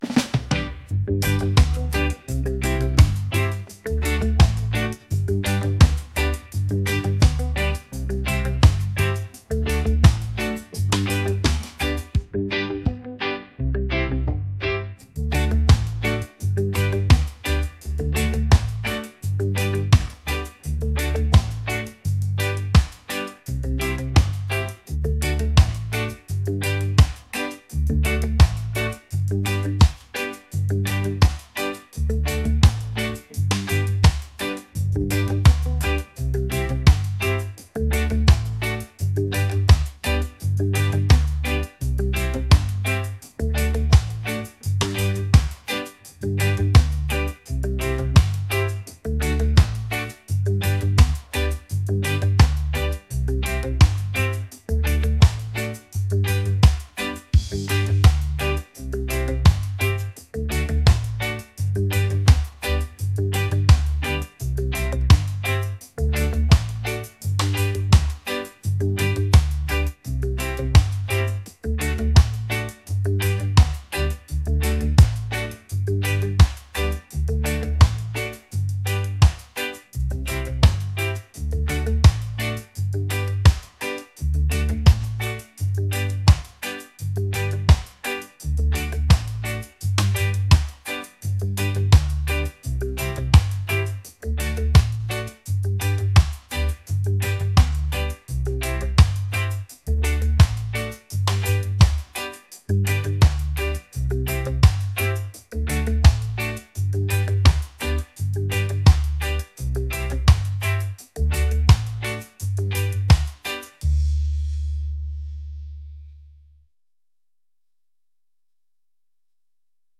reggae | upbeat